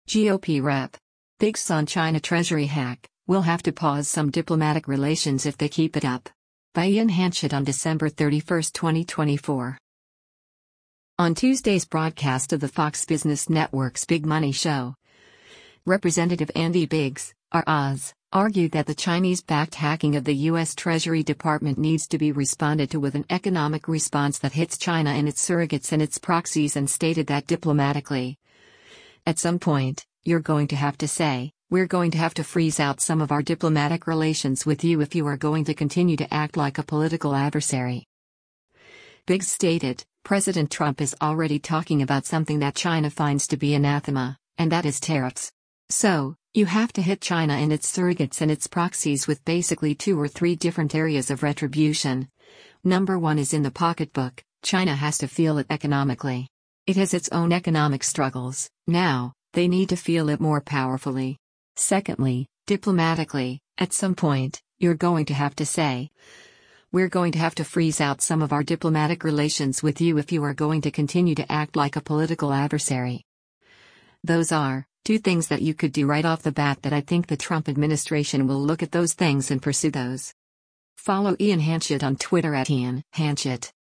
On Tuesday’s broadcast of the Fox Business Network’s “Big Money Show,” Rep. Andy Biggs (R-AZ) argued that the Chinese-backed hacking of the U.S. Treasury Department needs to be responded to with an economic response that hits “China and its surrogates and its proxies” and stated that “diplomatically, at some point, you’re going to have to say, we’re going to have to freeze out some of our diplomatic relations with you if you are going to continue to act like a political adversary.”